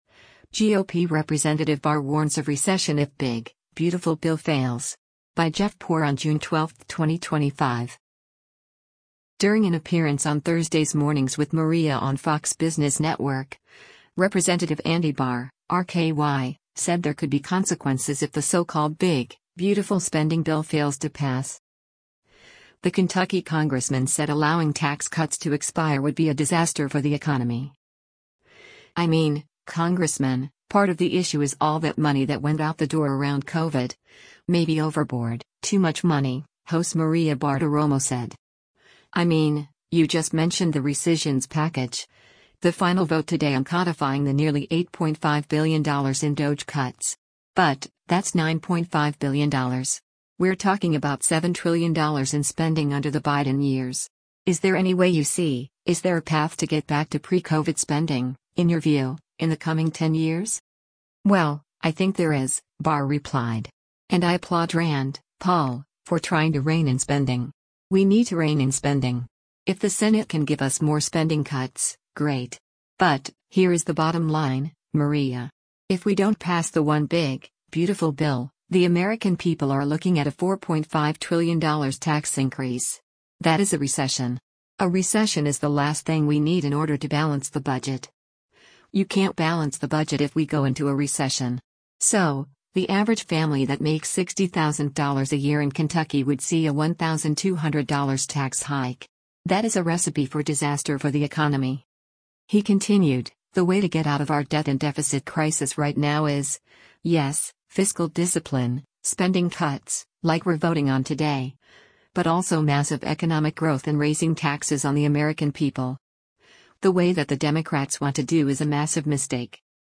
During an appearance on Thursday’s “Mornings with Maria” on Fox Business Network, Rep. Andy Barr (R-KY) said there could be consequences if the so-called big, beautiful spending bill fails to pass.